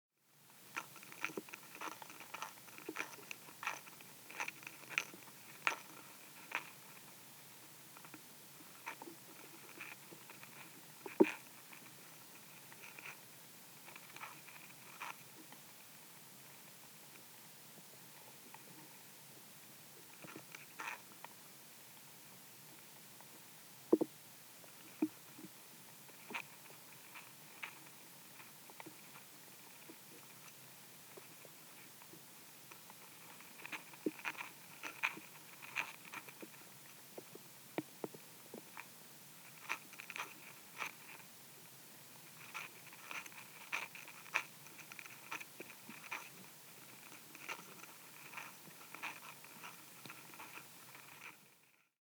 Beim Neue-Musik-Festival "Der Sommer in Stuttgart" im Wald aufgenommene Klänge, zum Anhören bitte anklicken:
Schaben in Boden und Baumhöhle,
SchabeninBodenBaumhoehle.mp3